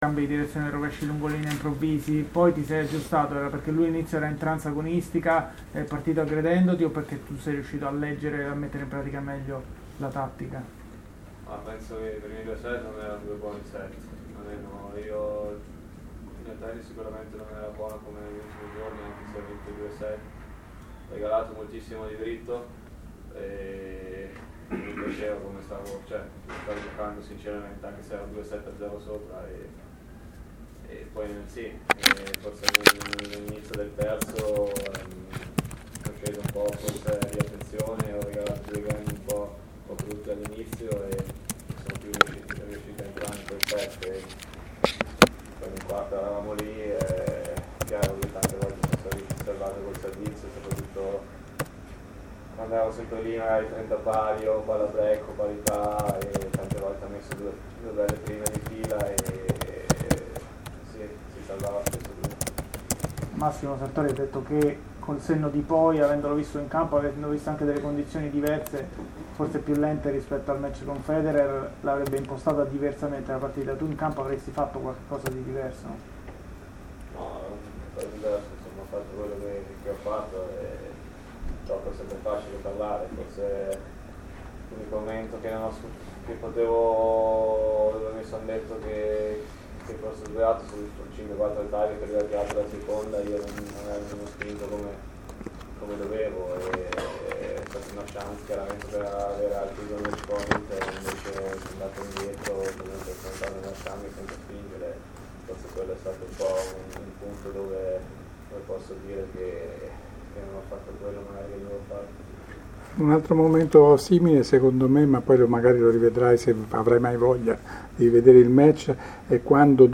Andreas Seppi in conferenza stampa: “Sono stato sfigato. Prossimo torneo?… lo US Open!”
Intervista-Seppi-dopo-Kyrgios.mp3